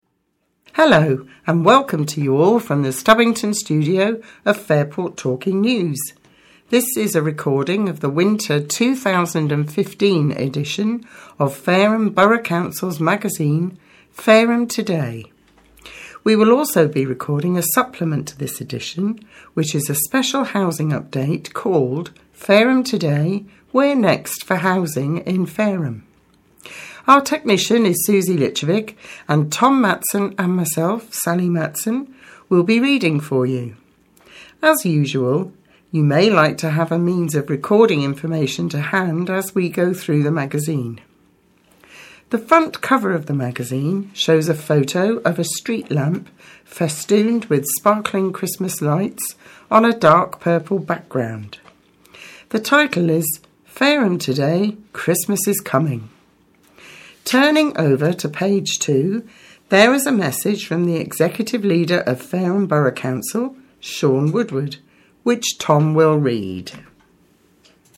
Introduction to presenters & description of front cover.